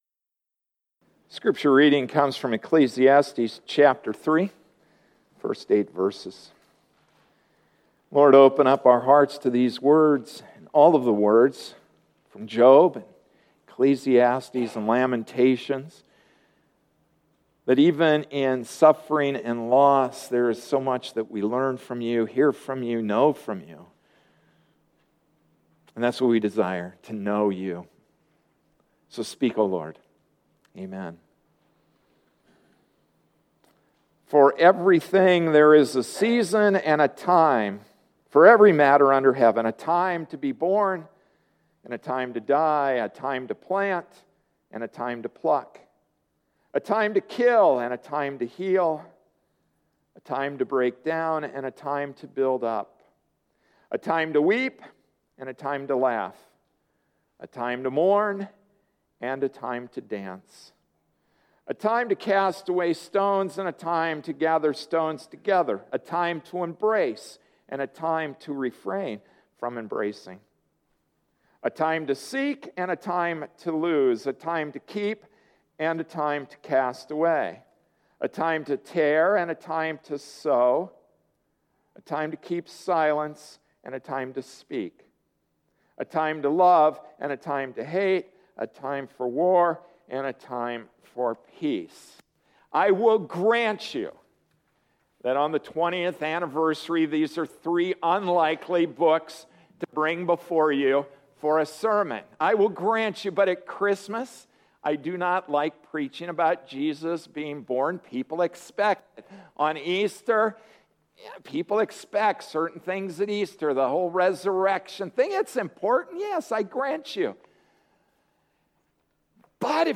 October 20, 2013 C Sharp Don’t B Flat Passage: Ecclesiastes 3:1-8 Service Type: Sunday Morning Service C-Sharp, Don't B-Flat Job: When you lose everything Ch1 Job’s property, plus his children, are taken from him.